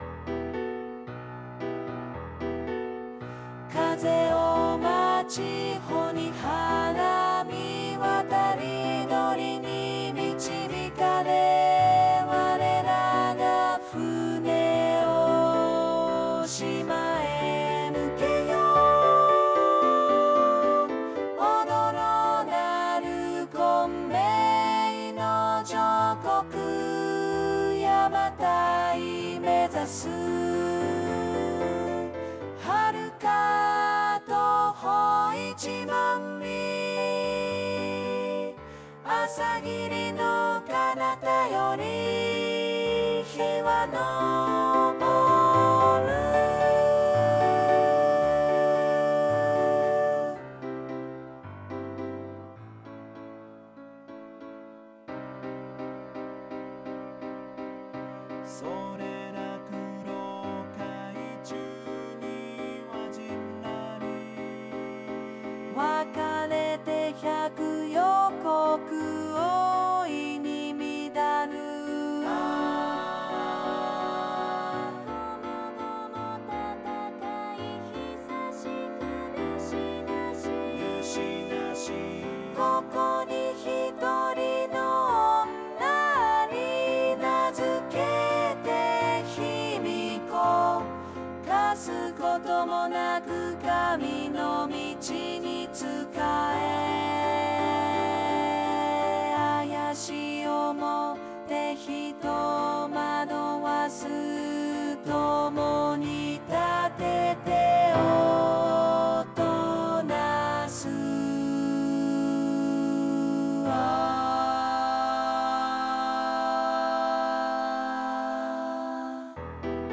航海(組曲「海の詩」より)　音取り音源(ボーカロイド版)